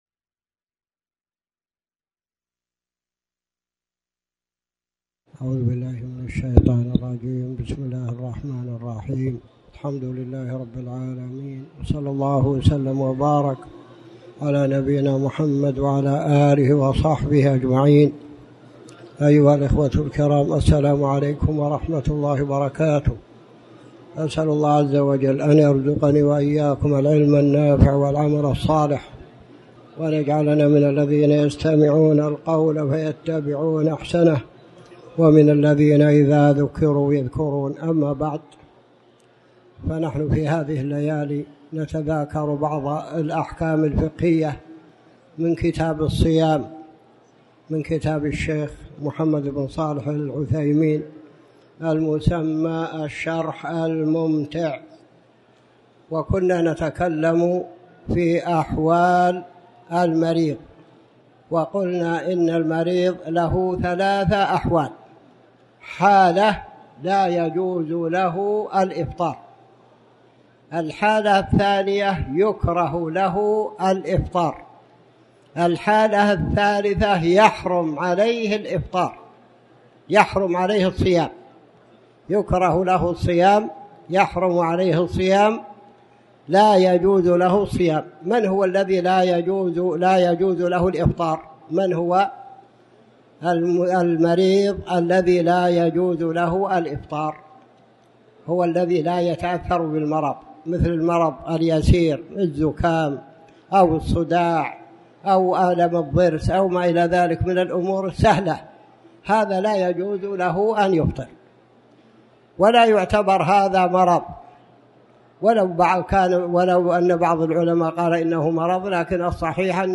تاريخ النشر ٢٧ شعبان ١٤٣٩ هـ المكان: المسجد الحرام الشيخ